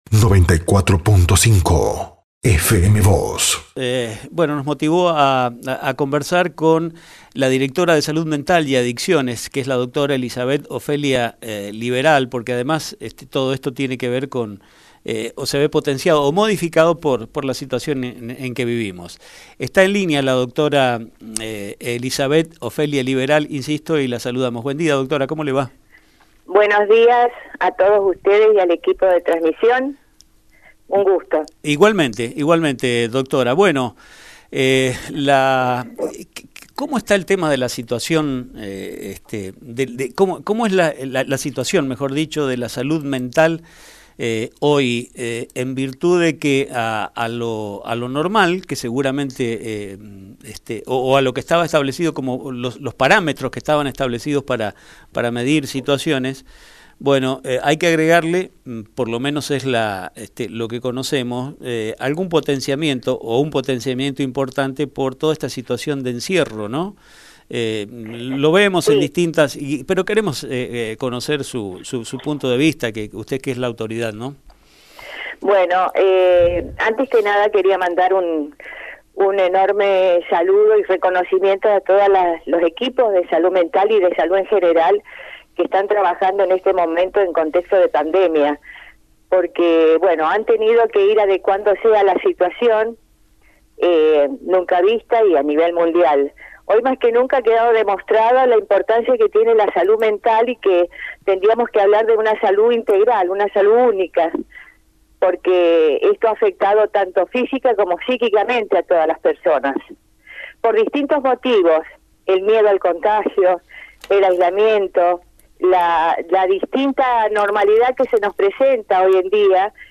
La doctora Elizabeth Ofelia Liberal, responsable de la Dirección de Salud Mental y Adicciones de la provincia, destacó en diálogo con FM Vos (94.5) y a Diario San Rafael, el trabajo que los equipos de salud mental y de salud en general que están trabajando en este contexto de pandemia, pues han tenido que adecuarse a una situación vista.